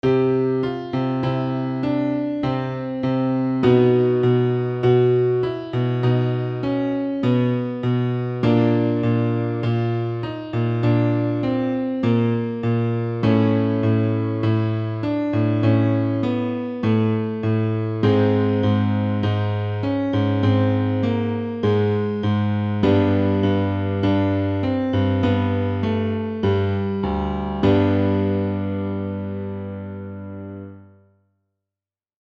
リップロールで下行５度スケールでクールダウン
「ソファミレドー」の音階で半音ずつ下行しながら、リップロールを行います。
５TONE下行スケール
5tone_down.mp3